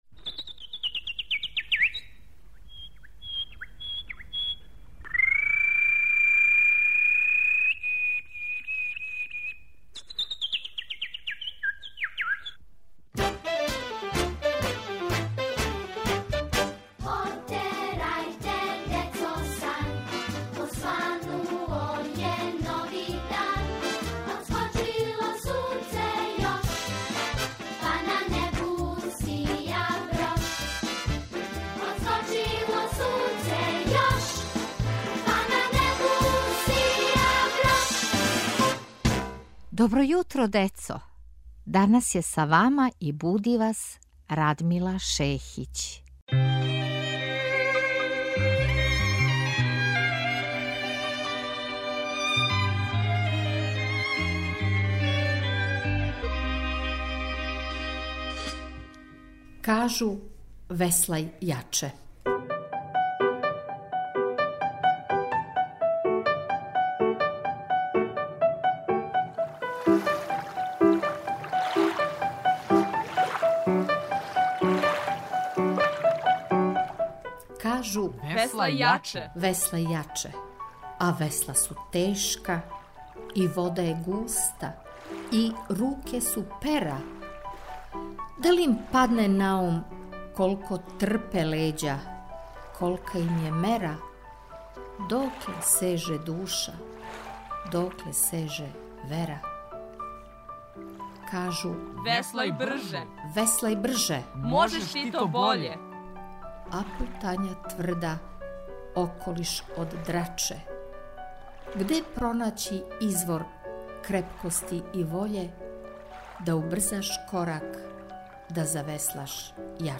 Поезија